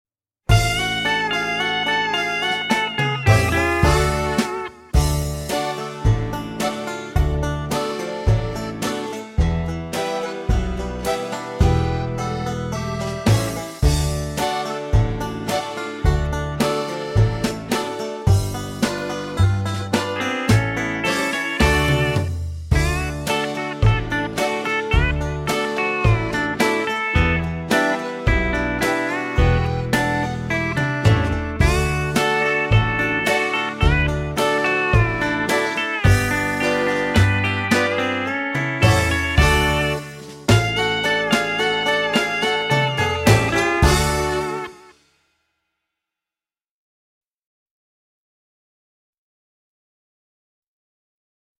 VS Old-Timer (backing track)